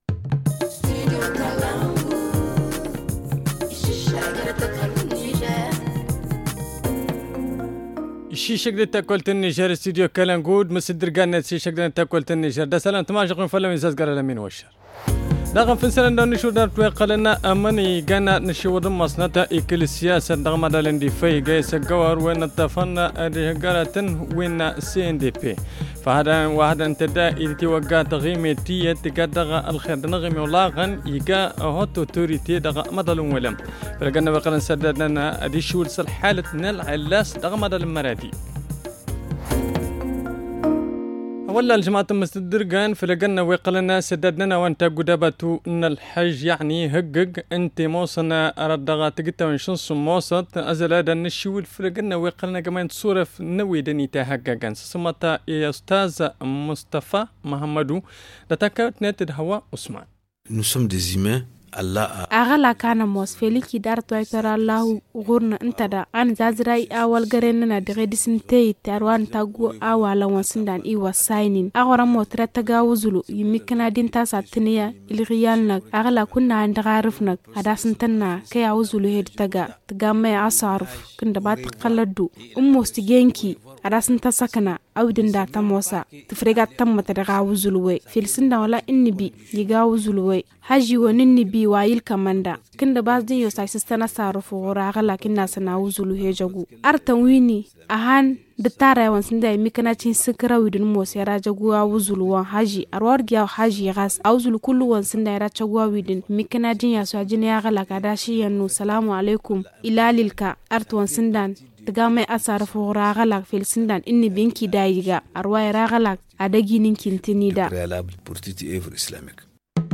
Le journal du 7 juin 2023 - Studio Kalangou - Au rythme du Niger